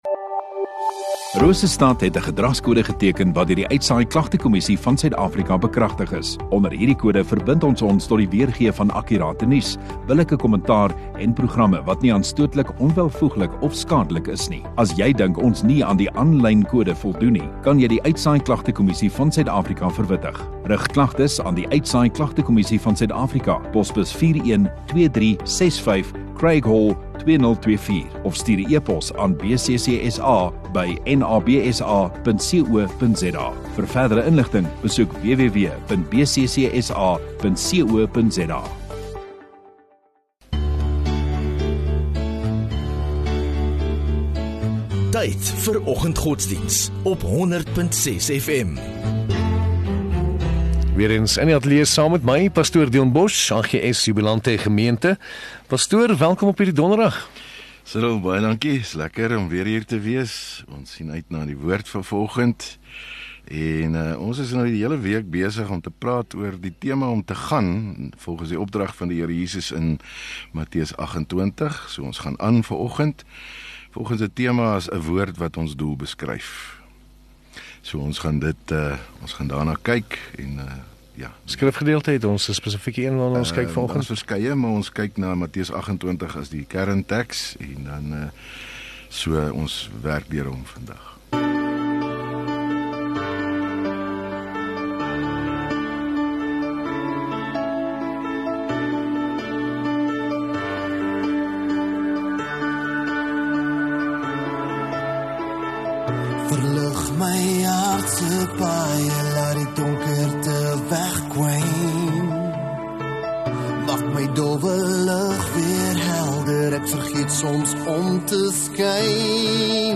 Godsdiens